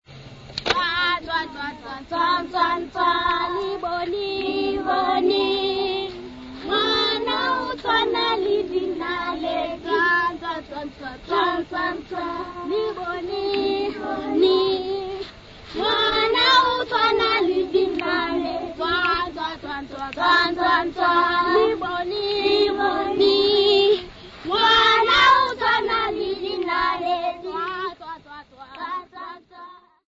Sesotho children (Performer)
Shongoane village
Indigenous music
Traditional music
Unaccompanied Sesotho wedding song
Cassette tape